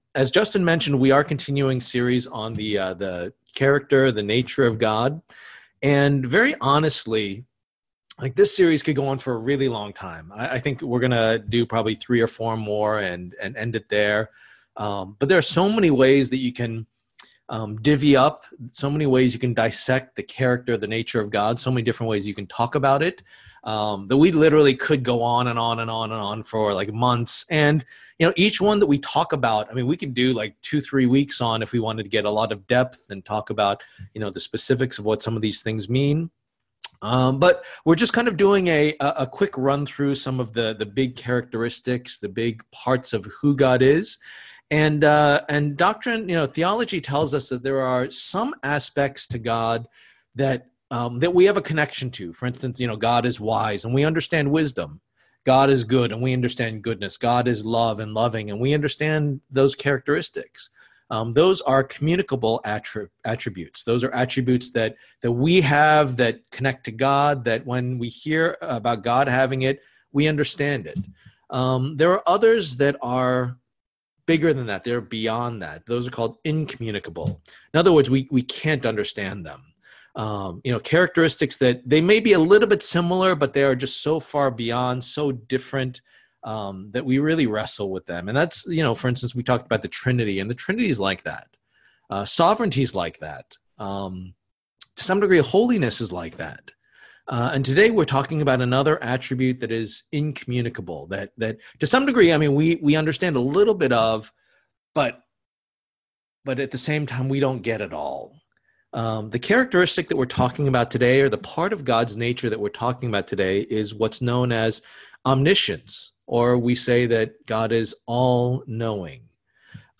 Passage: Psalm 139:1-24 Service Type: Lord's Day